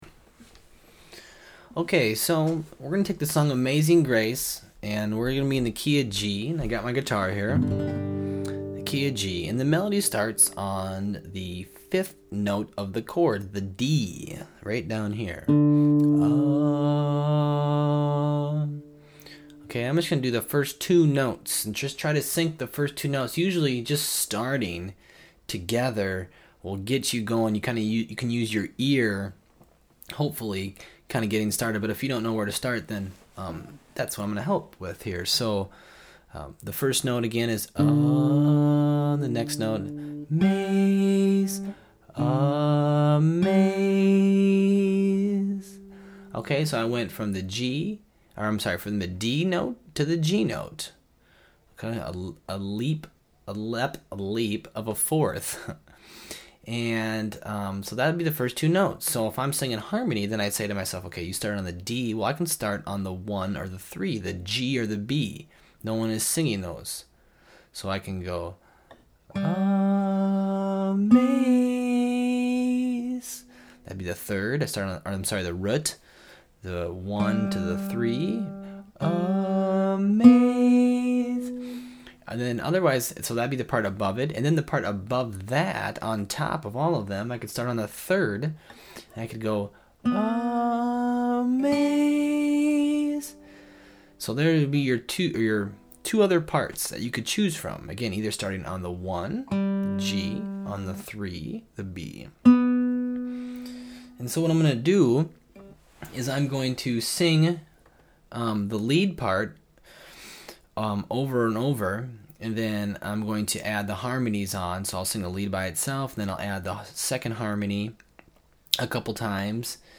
Lets take the song Amazing Grace. For this example we’ll be in the key of G. The I, III, V notes are G, B, D. They make a triad or a chord.